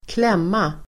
Uttal: [²kl'em:a]